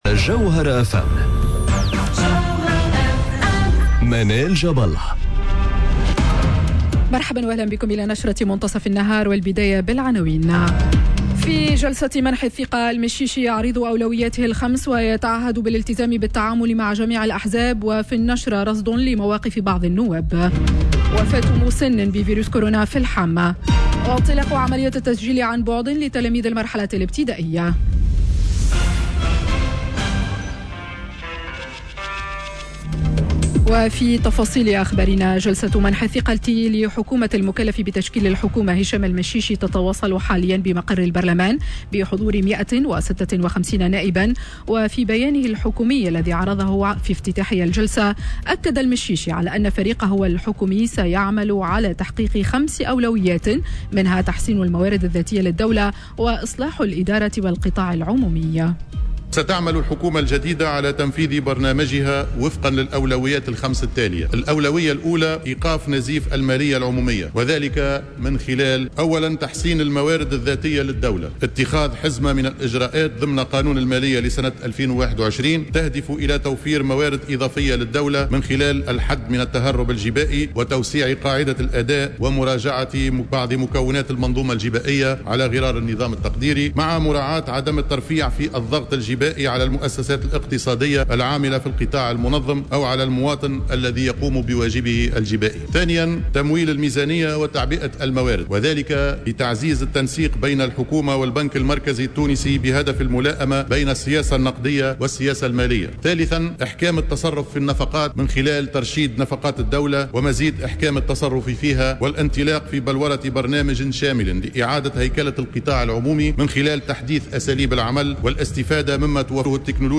نشرة أخبار منتصف النهار ليوم الثلاثاء 01 سبتمبر 2020